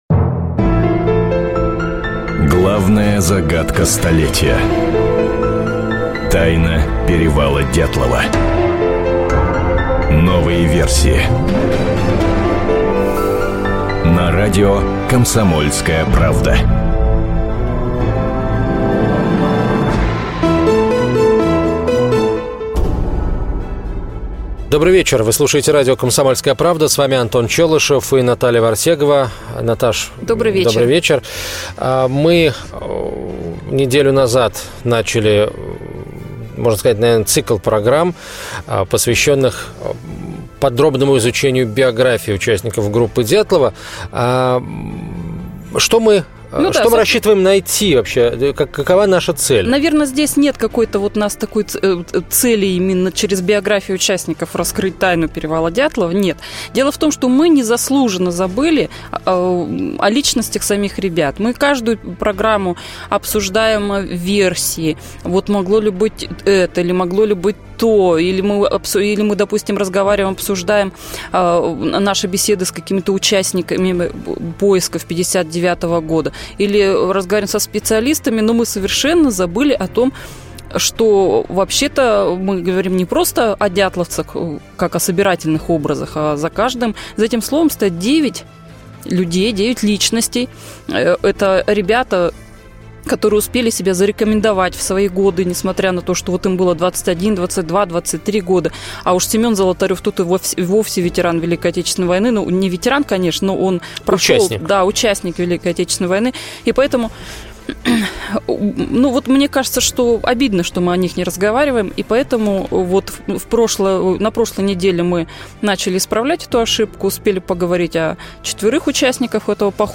Аудиокнига Жизнь до Перевала: биография участников группы Дятлова | Библиотека аудиокниг